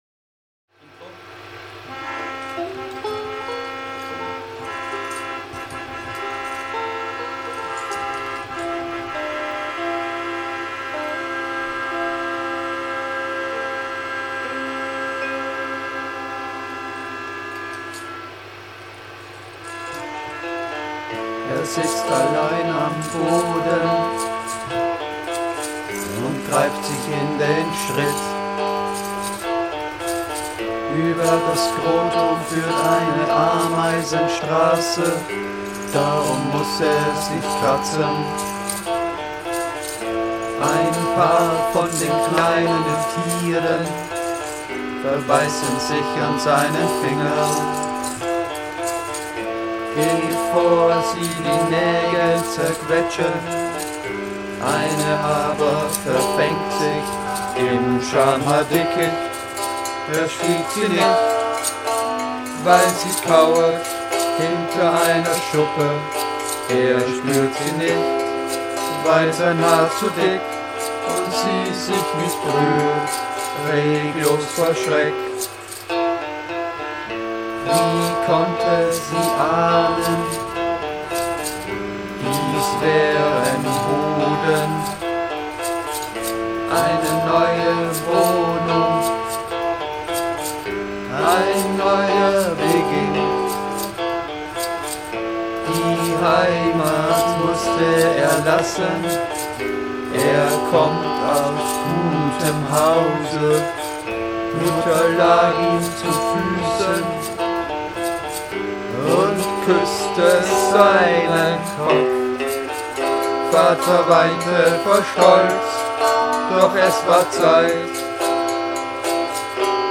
Literaturlieder von brüchiger Schönheit, verwegene Tongänge